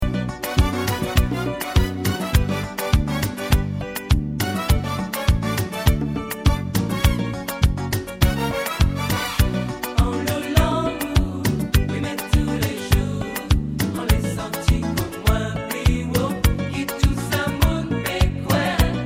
Le style : Zouk Love Antillais aux influences Reggae, Ragga